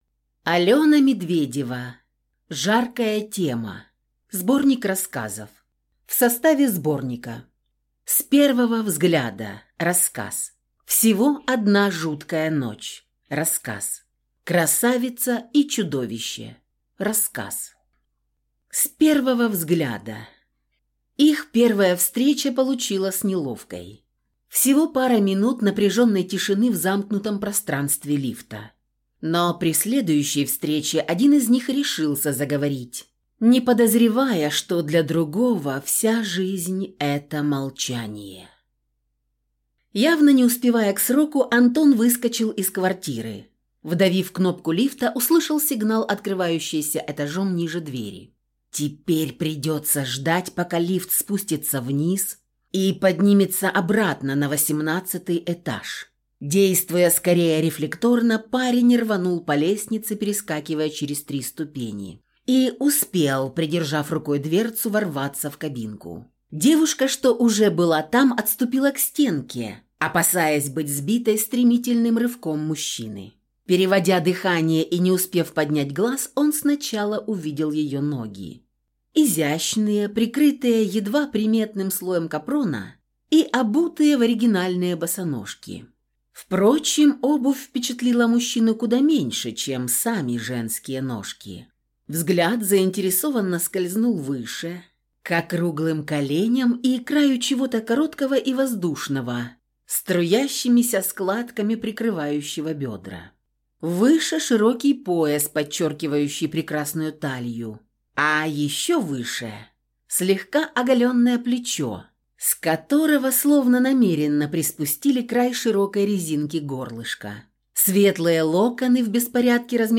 Аудиокнига Жаркая тема | Библиотека аудиокниг